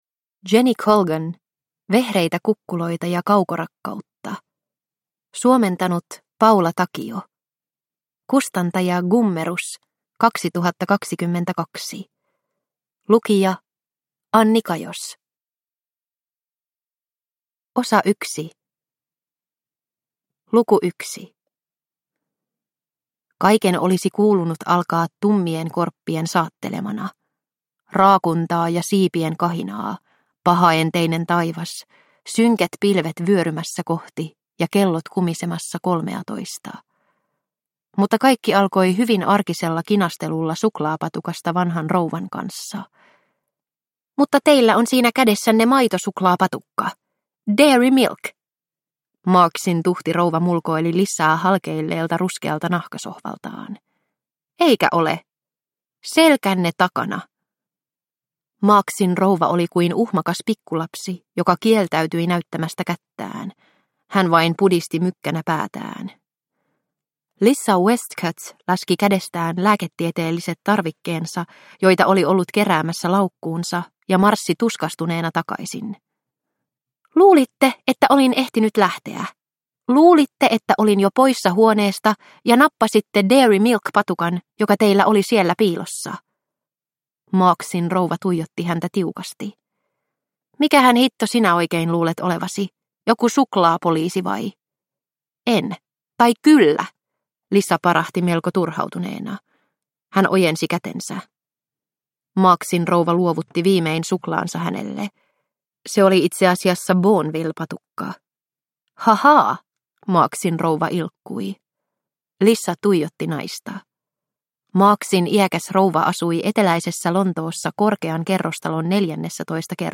Vehreitä kukkuloita ja kaukorakkautta – Ljudbok – Laddas ner